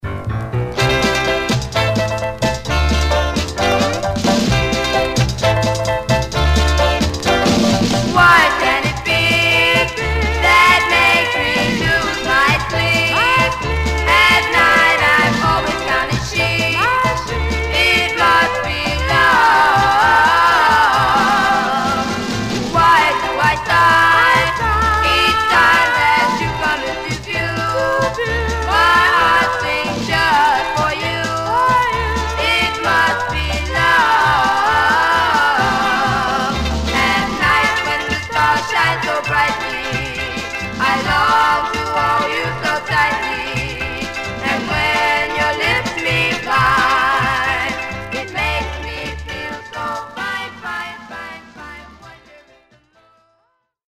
Mono
Black Female Group Condition